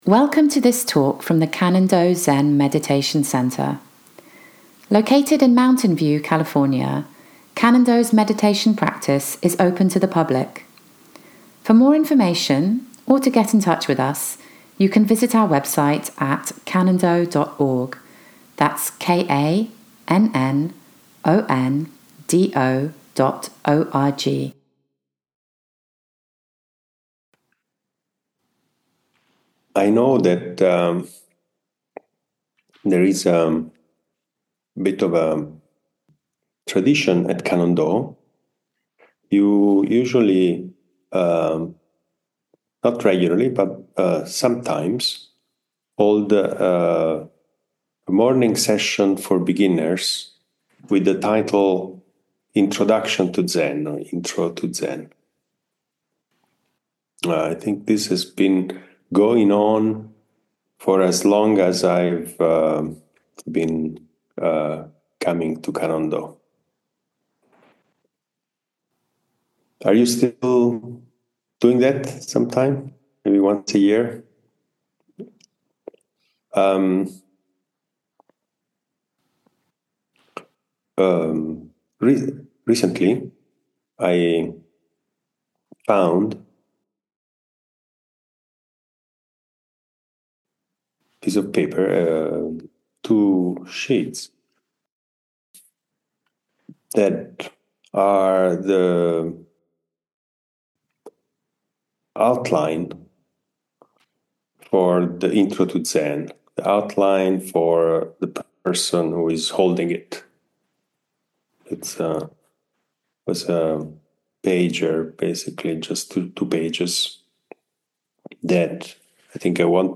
Zen Center in Mountain View California